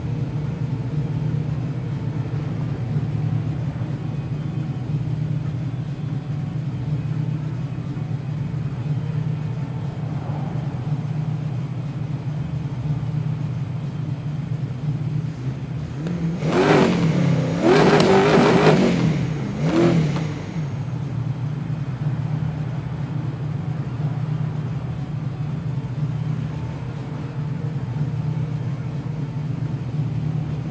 idle.wav